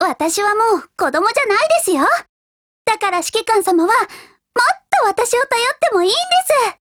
贡献 ） 协议：Copyright，其他分类： 分类:少女前线:MP5 、 分类:语音 您不可以覆盖此文件。